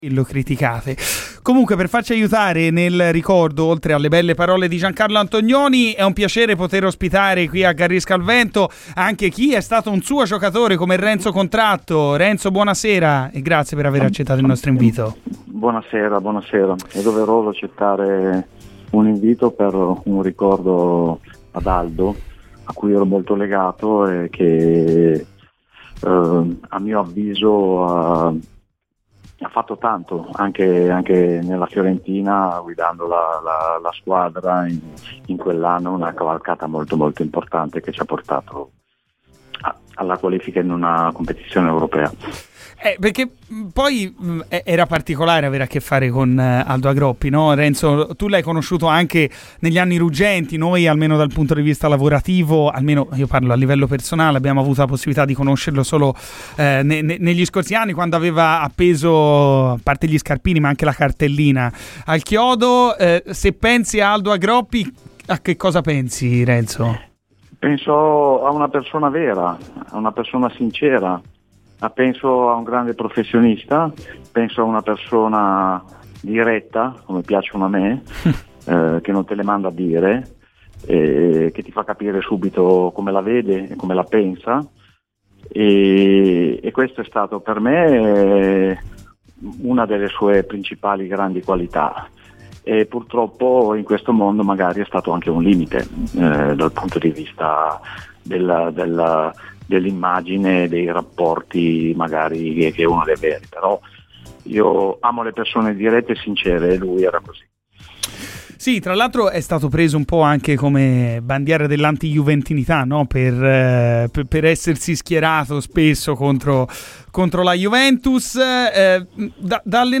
Intervenuto a Radio FirenzeViola durante 'Garrisca al Vento'